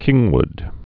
(kĭngwd)